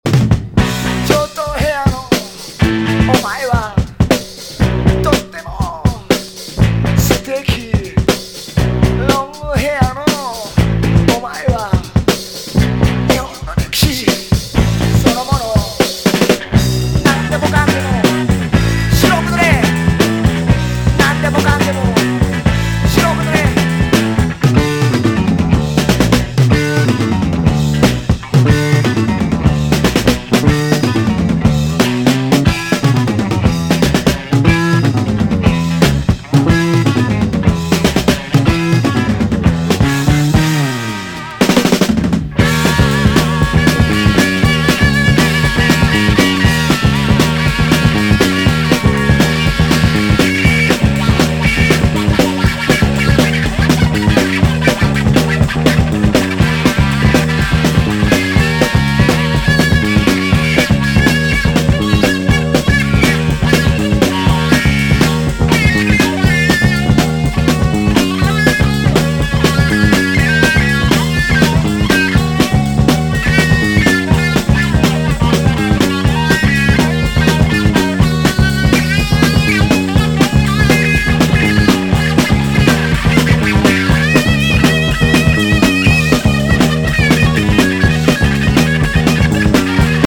一度聴いたら忘れさせてくれないアシッド・フォークに通ずる世界。